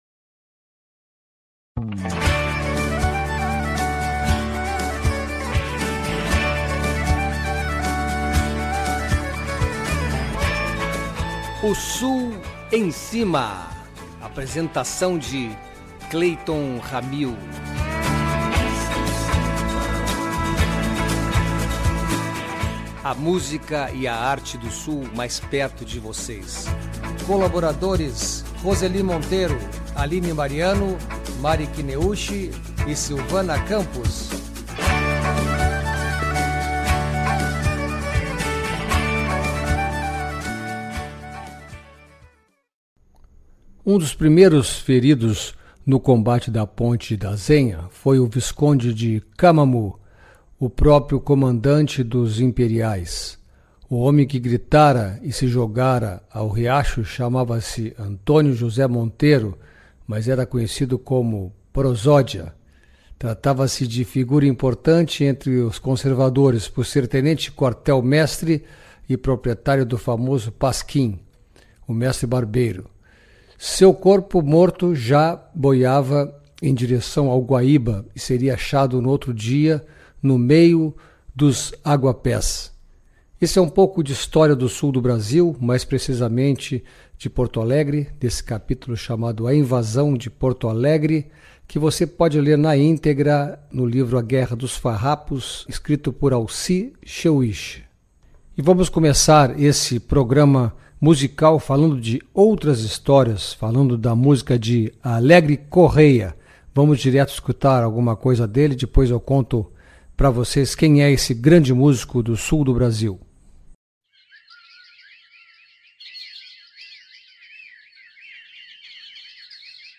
Sua música é sensual e inteligente, complexa e cheia de sentimento,com muito groove e elegante! Vamos ouvir um pouquinho o trabalho interessante desse talentoso músico que valoriza suas raízes brasileiras mas tem as portas abertas para outras influências diversas.